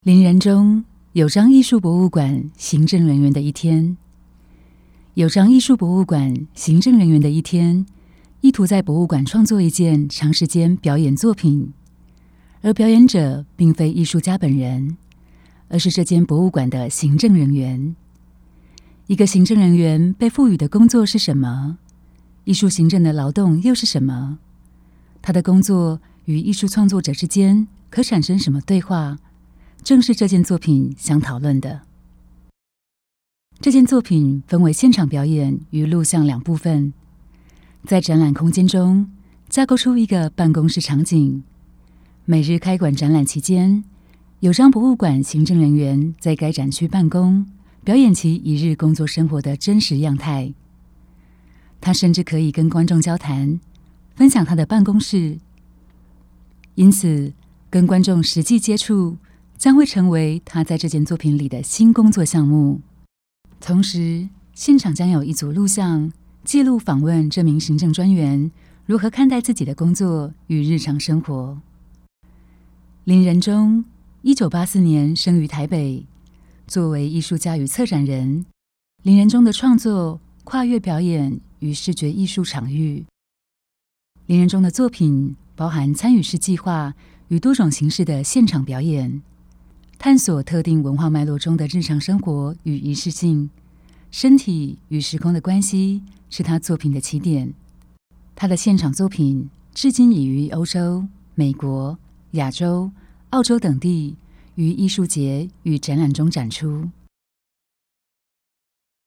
作品導覽 http